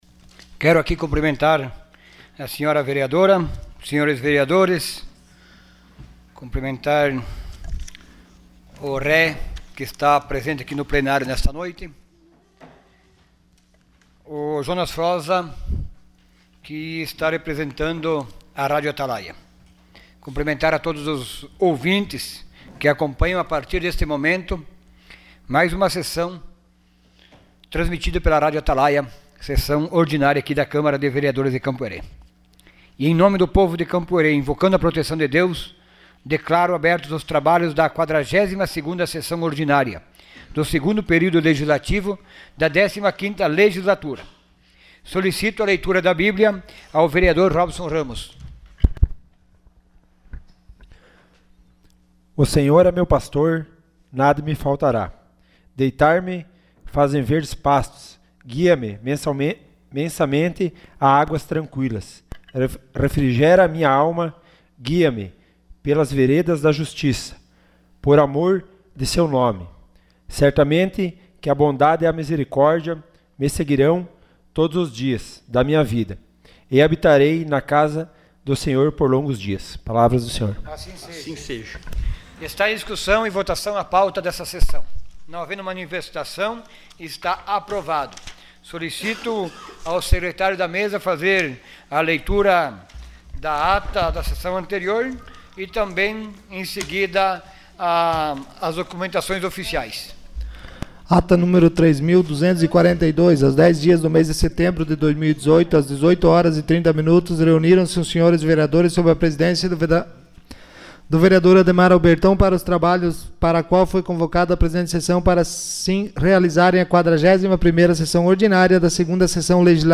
Sessão Ordinária dia 12 de setembro de 2018.